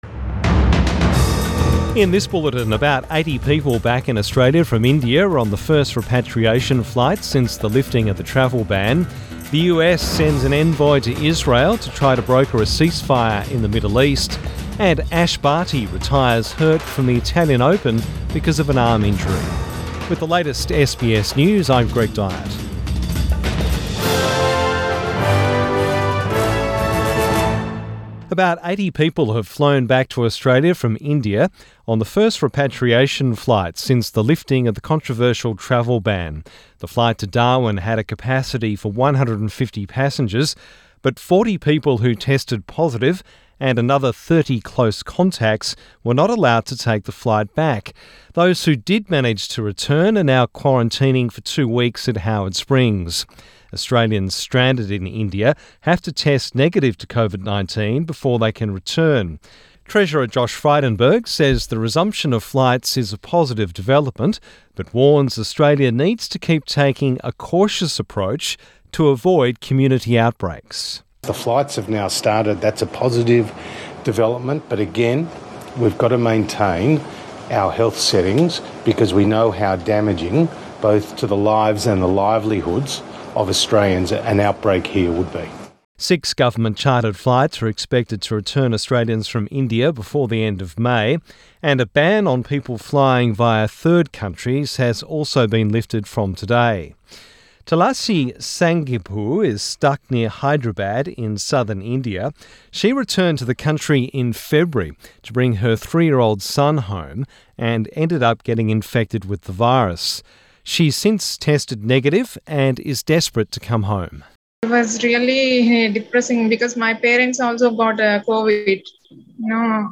PM bulletin 15 May 2021